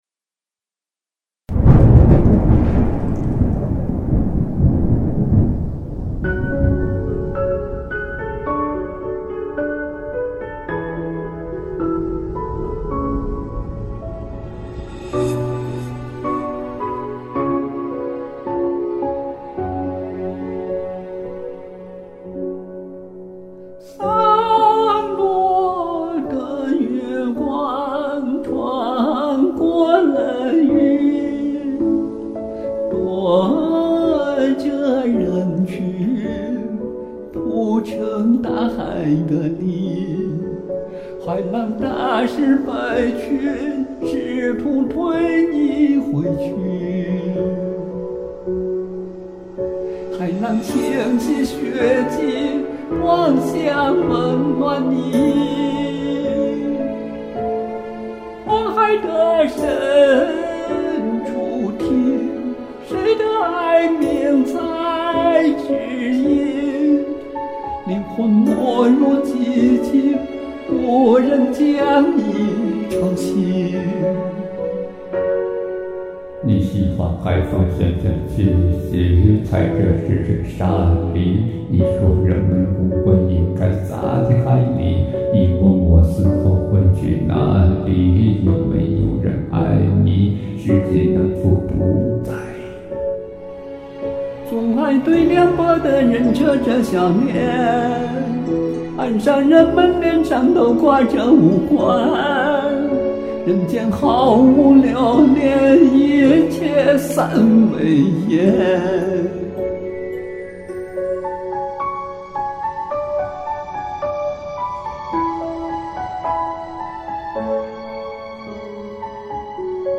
嚯，这嗓子转换自如，悲伤的气氛好浓，很有现场感，赞你小嗓子！
高低音转换很自如！低音很结实！悲伤的情感恰到好处！